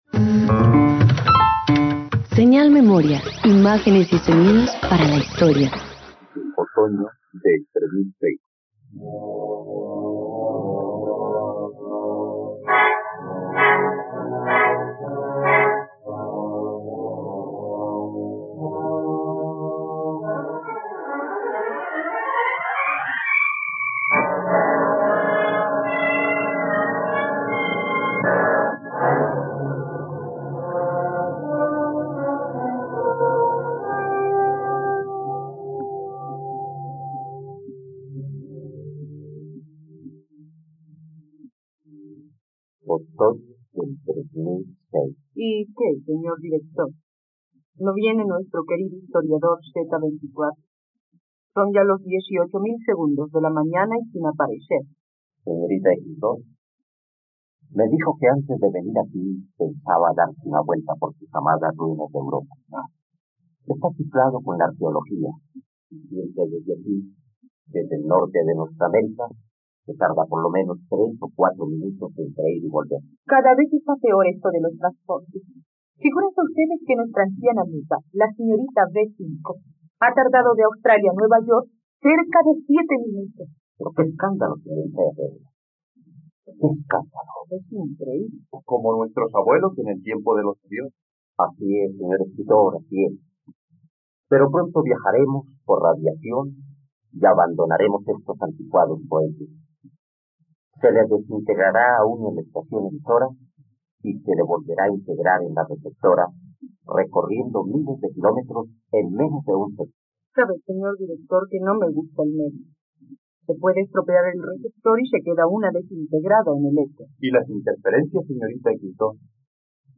..Radioteatro. Escucha la adaptación radiofónica de la obra ‘Otoño del 3006’ del dramaturgo español Agustín de Foxá en la plataforma de streaming RTVCPlay.